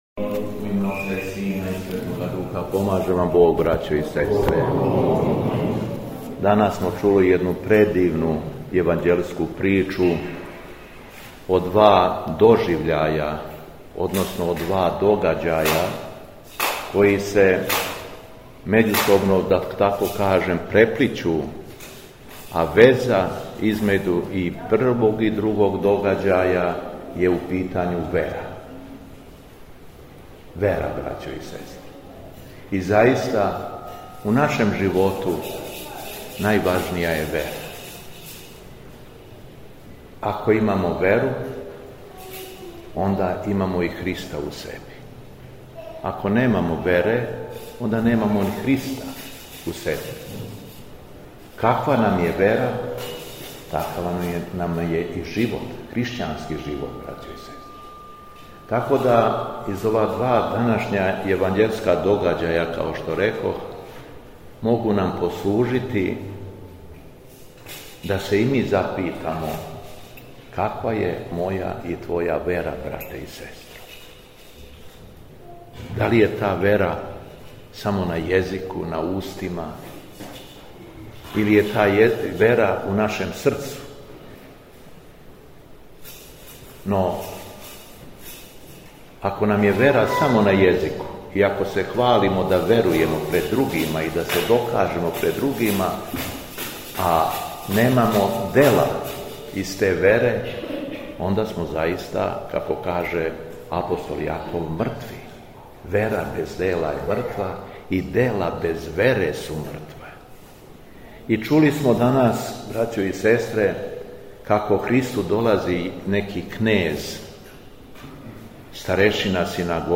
ЛИТУРГИЈУ У СЕЛУ ЧИБУТКОВИЦА - Епархија Шумадијска
Беседа Његовог Високопреосвештенства Митрополита шумадијског г. Јована
Присуствовао је верни народ колубарско-посавског намесништва уз присуство и активно учешће многобројне деце овог и околних села.